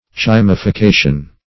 Search Result for " chymification" : The Collaborative International Dictionary of English v.0.48: Chymification \Chym`i*fi*ca"tion\, n. [Chyme + L. facere to make: cf. F. Chymification.]
chymification.mp3